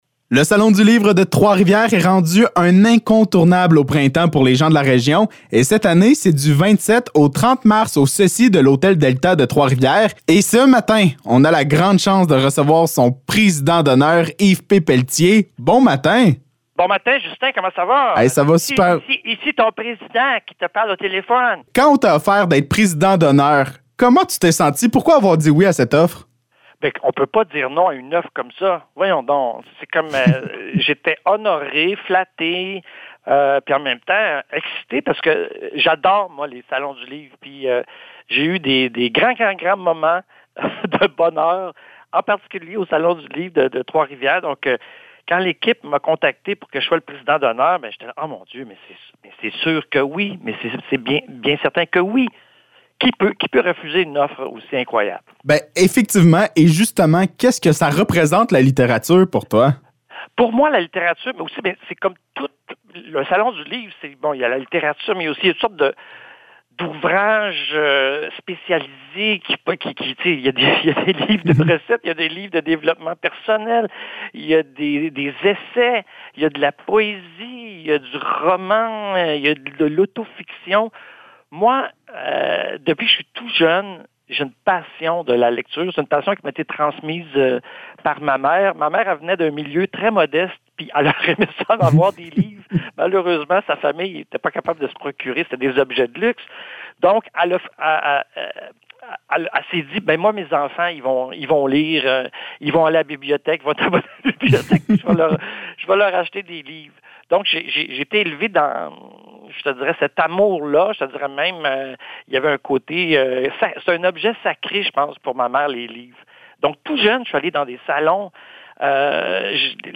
Entrevue avec Yves P. Pelletier